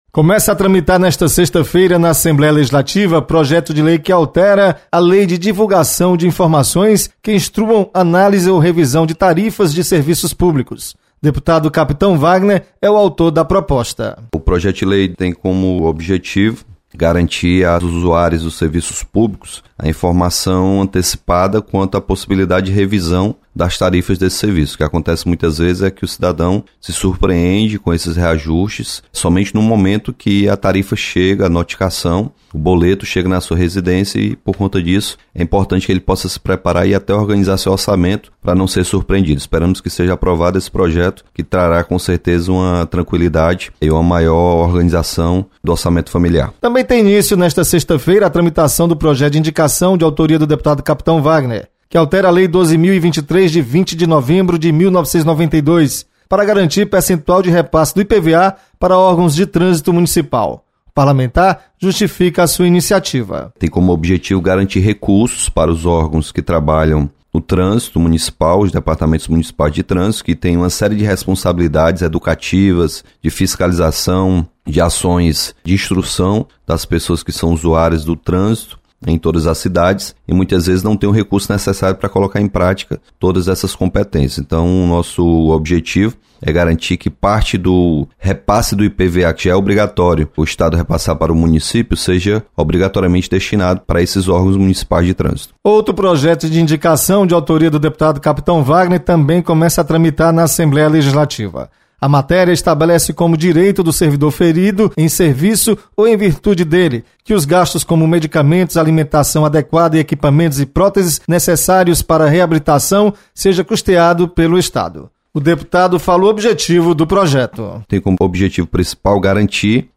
Projeto beneficia servidor público. Repórter